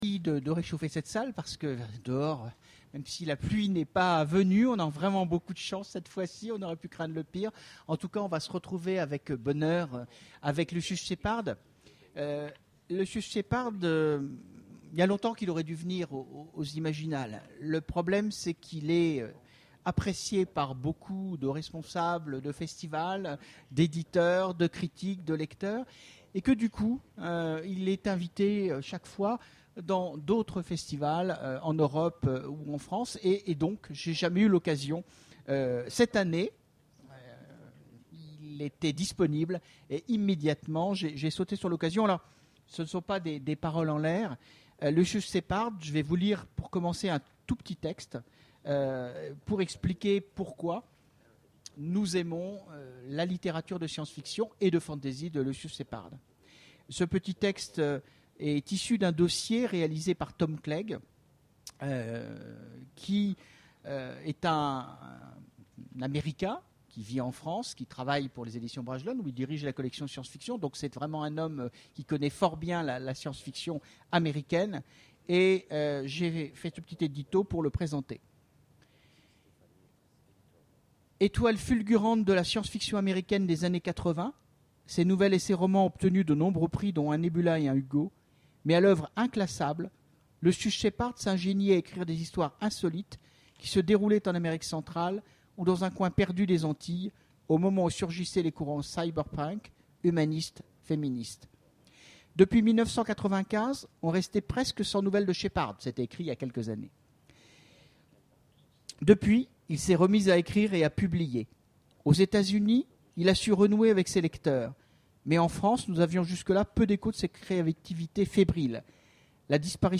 Imaginales 2013 : Conférence Entretien avec... Lucius Shepard
Lucius Shepard Télécharger le MP3 à lire aussi Lucius Shepard Genres / Mots-clés Rencontre avec un auteur Conférence Partager cet article